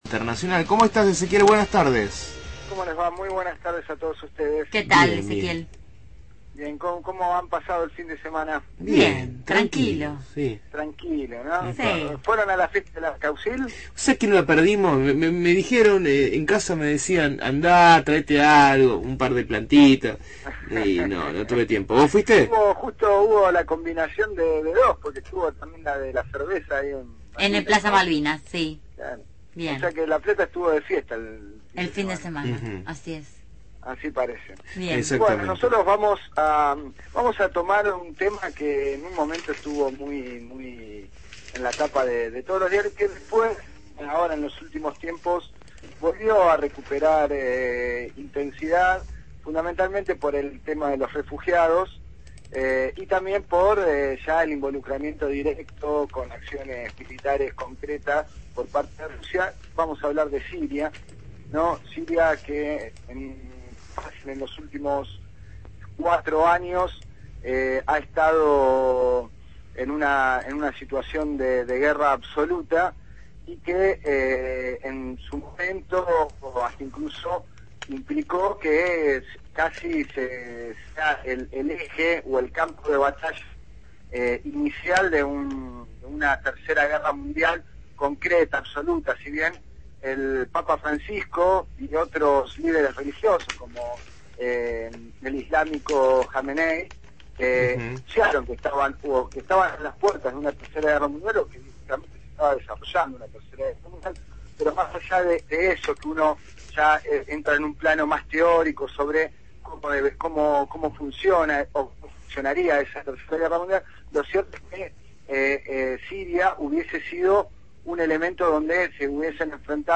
realizó su habitual columna de información internacional en «Dame una señal», en esta oportunidad dedicada a la situación actual en Siria.